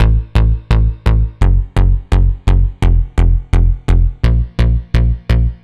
Index of /musicradar/80s-heat-samples/85bpm
AM_OB-Bass_85-A.wav